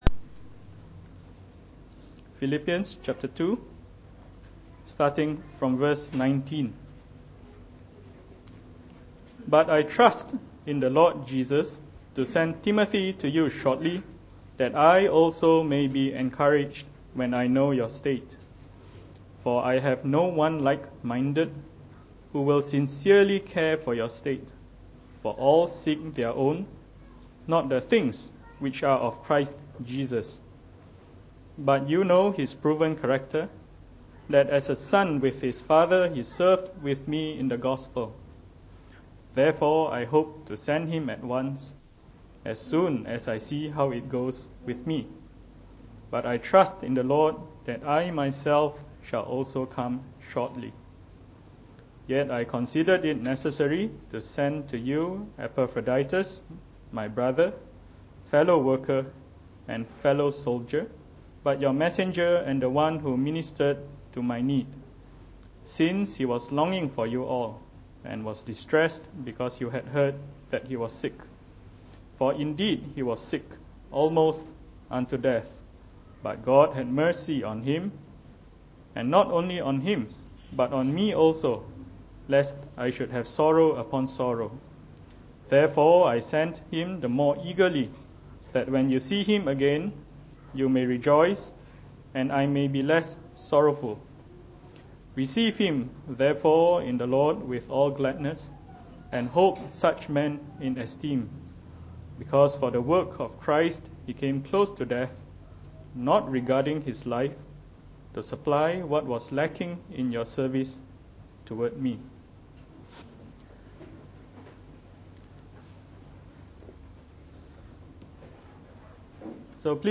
Preached on the 19th of February 2017.